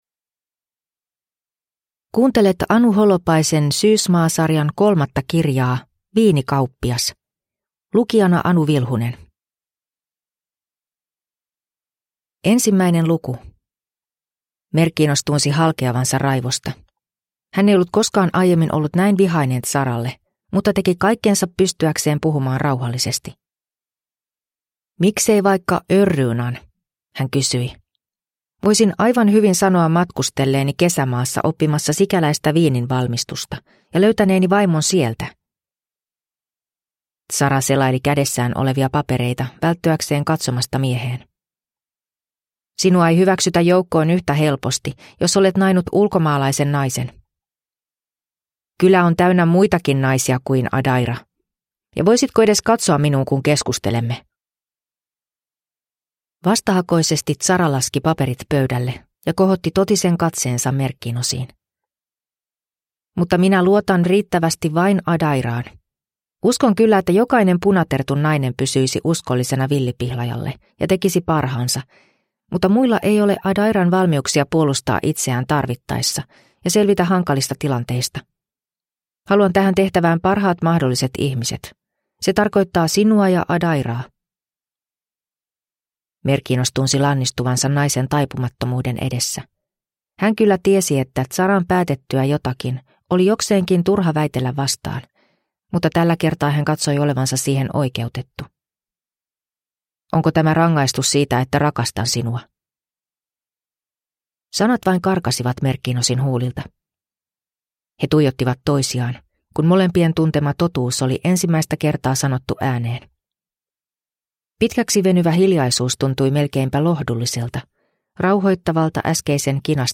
Viinikauppias – Ljudbok